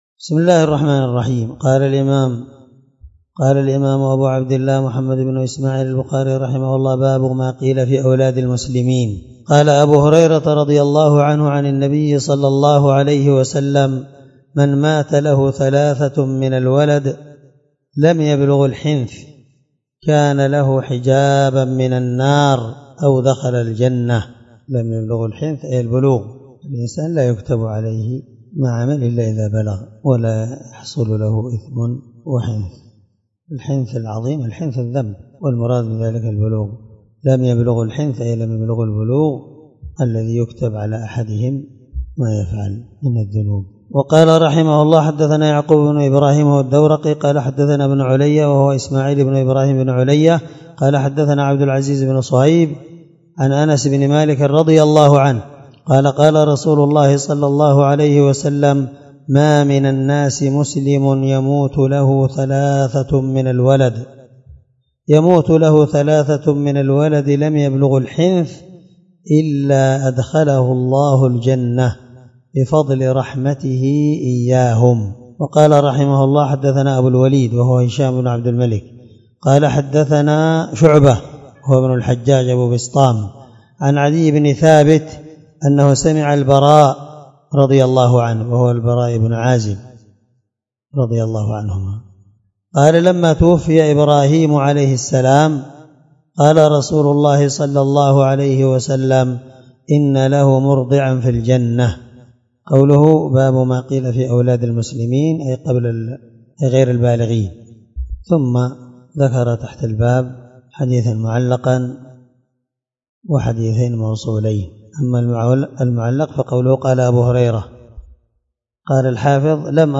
سلسلة_الدروس_العلمية
دار الحديث- المَحاوِلة- الصبيحة